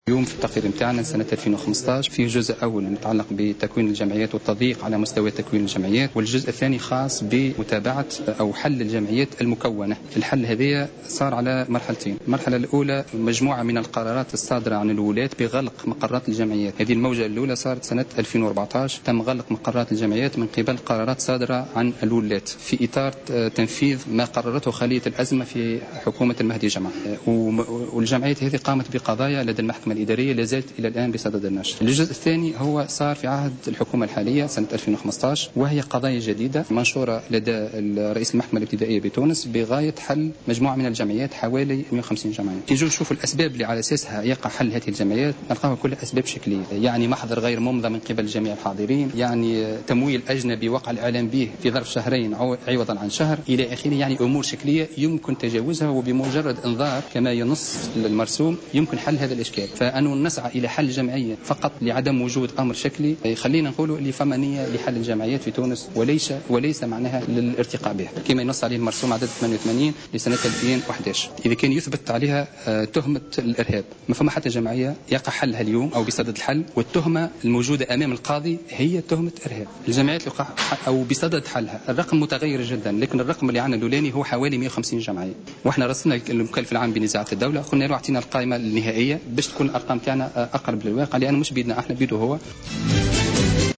وقال في تصريح اليوم لمراسل "الجوهرة أف أم" على هامش ندوة صحفية نظمها المرصد إن الحكومة تسعى للتضييق على الجمعيات بدعوة مكافحة الإرهاب، مشيرا إلى وجود توجه لحل حوالي 150 جمعية لأسباب "شكلية"، يمكن تجاوزها وفق تعبيره.